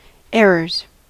Ääntäminen
Ääntäminen US Tuntematon aksentti: IPA : /ˈeɹəz/ Lyhenteet ja supistumat (laki) Err.